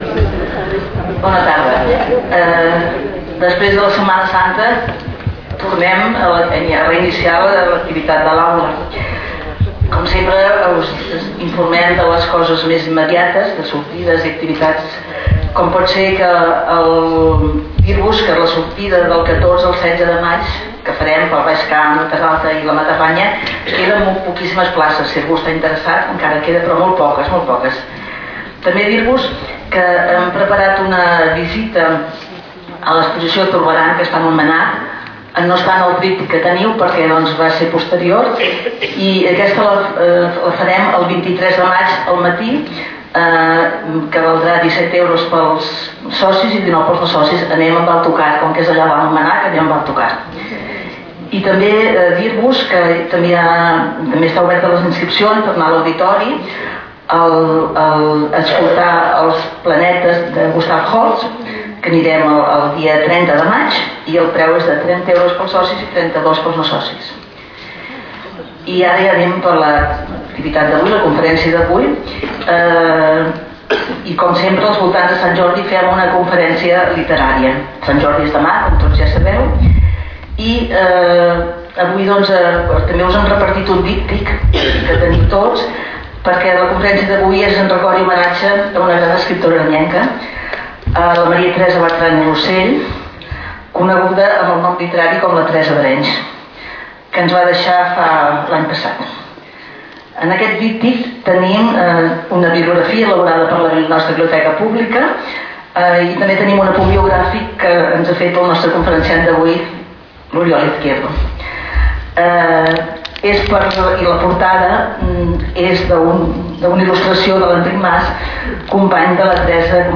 Lloc: Sala d'actes del Col.legi La Presentació
Categoria: Conferències